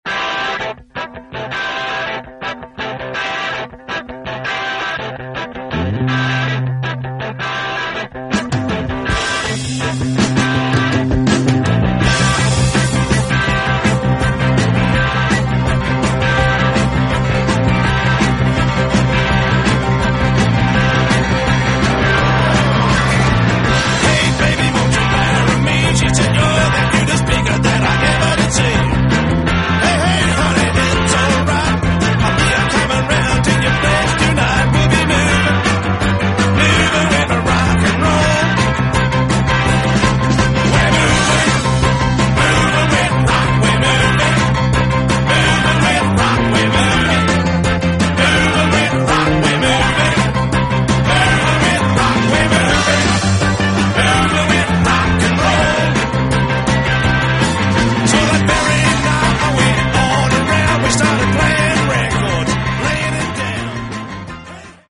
piano player extraordinaire